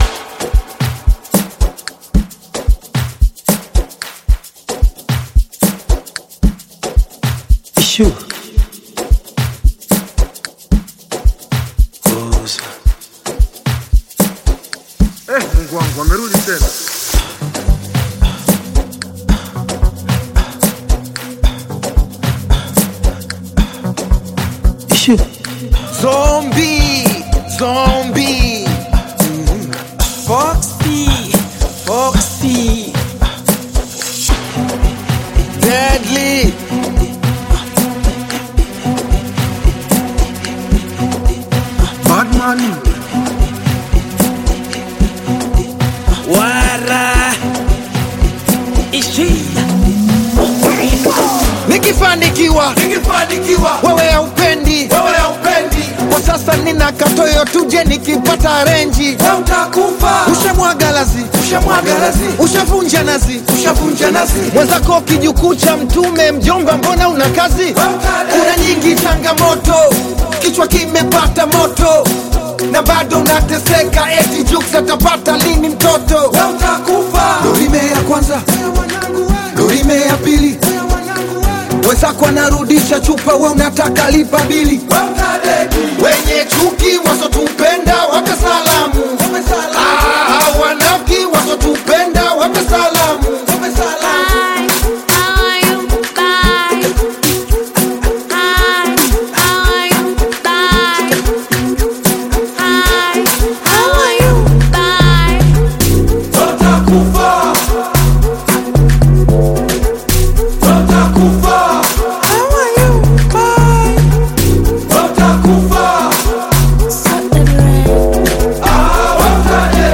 Amapiano
Bongo Flava